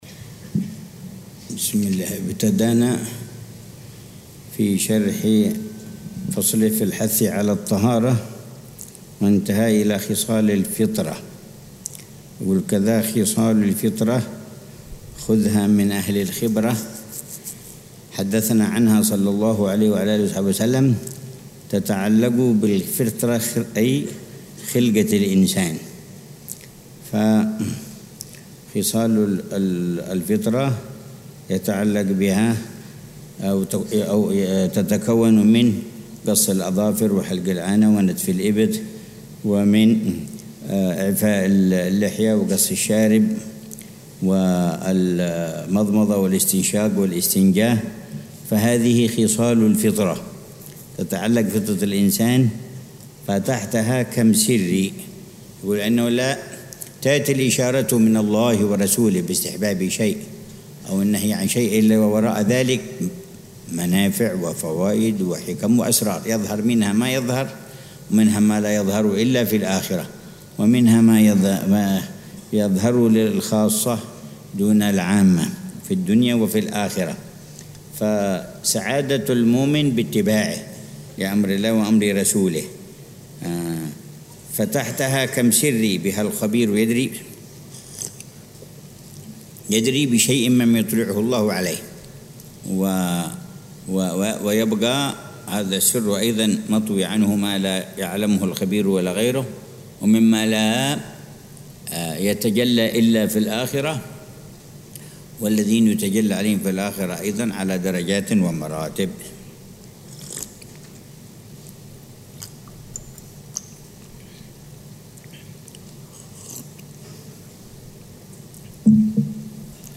شرح الحبيب عمر بن حفيظ على منظومة «هدية الصديق للأخ والرفيق» للحبيب عبد الله بن حسين بن طاهر. الدرس السادس عشر